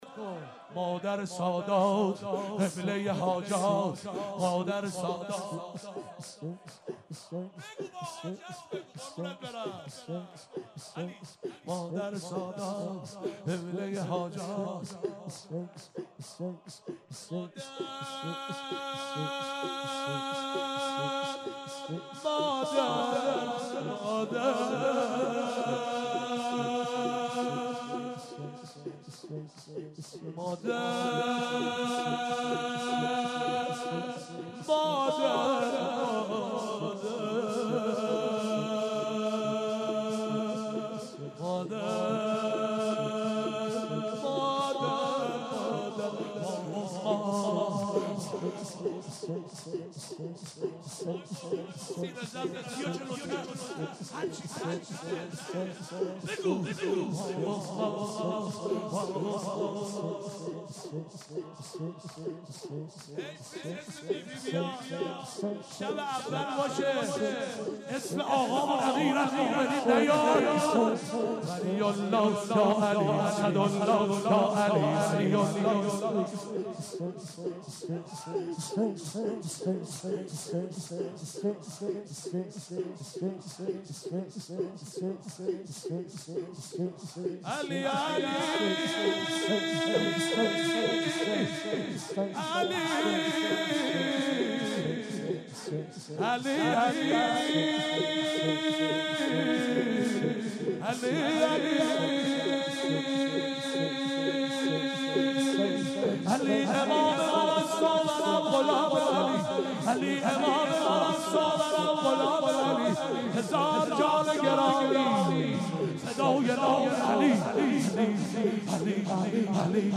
شور-شب اول- محرم96
محرم96-شب اول